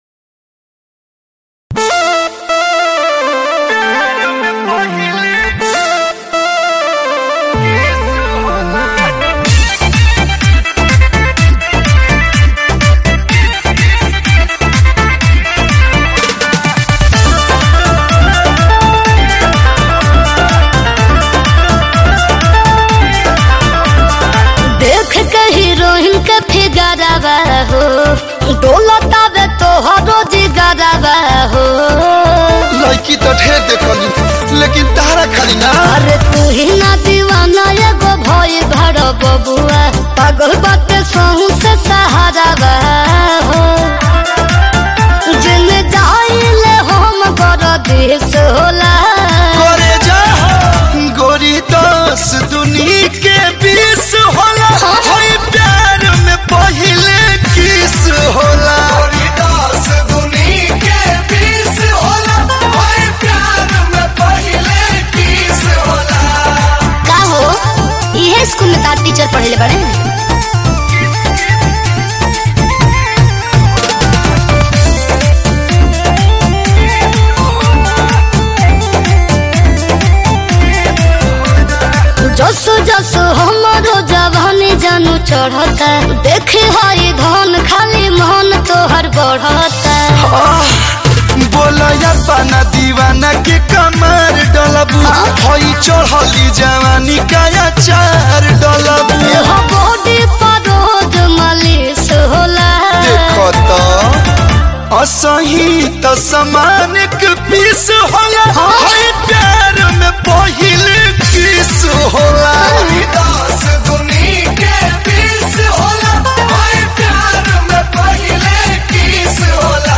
Bhojpuri